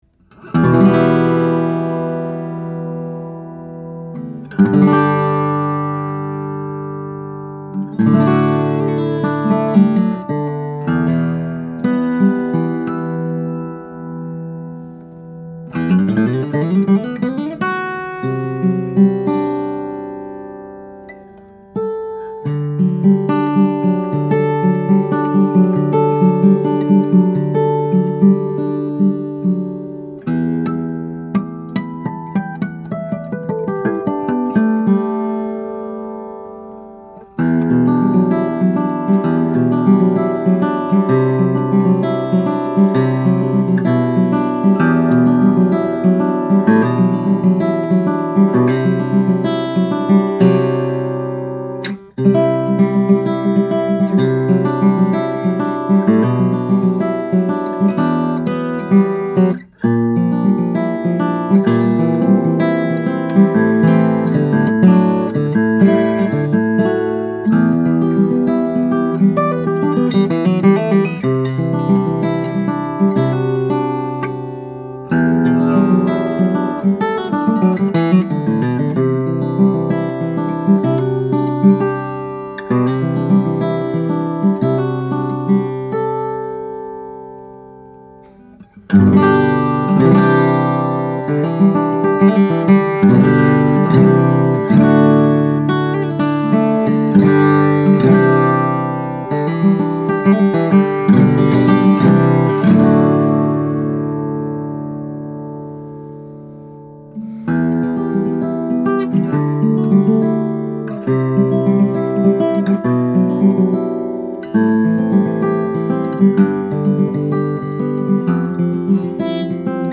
The top is torrefied Adirondack spruce, providing both headroom and a quick response, with the added warmth and openness that comes from torrefaction.